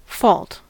fault: Wikimedia Commons US English Pronunciations
En-us-fault.WAV